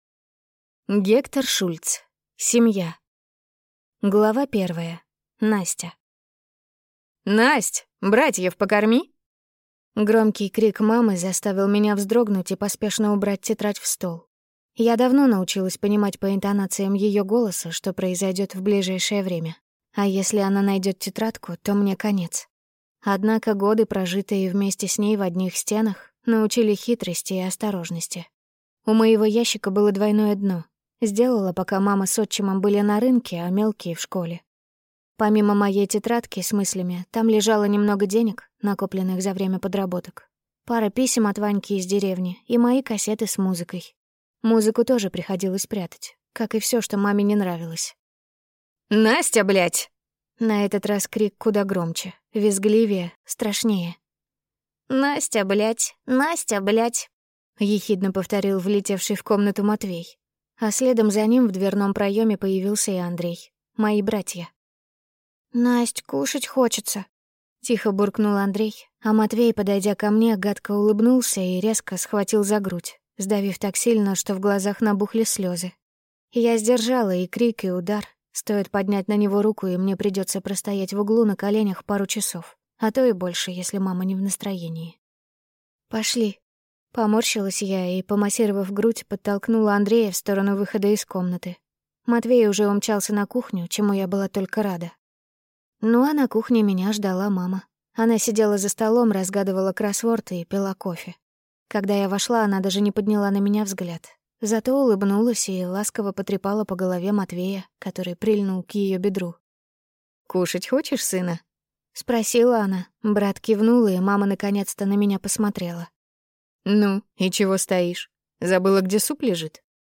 Аудиокнига Семья | Библиотека аудиокниг